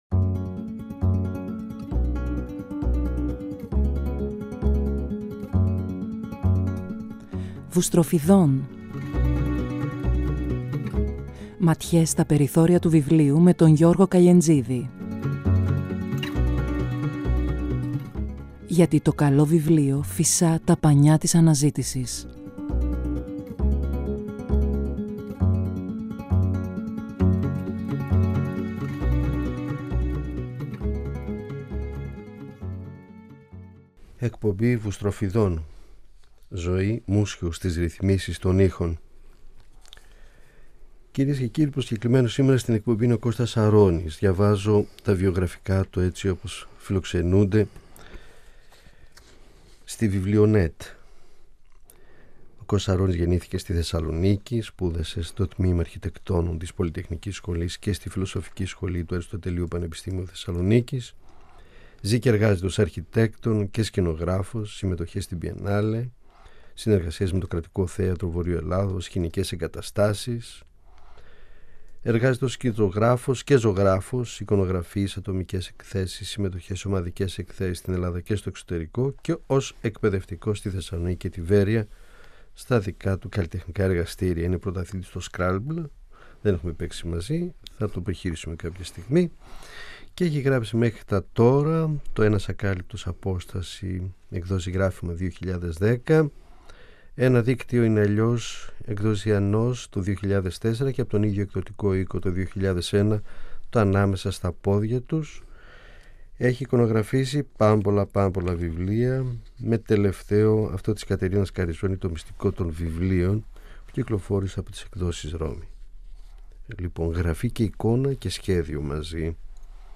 Διαβάζει αποσπάσματα από το έργο του και μιλά για το πώς η γραφή αλληλεπιδρά με τις άλλες τέχνες.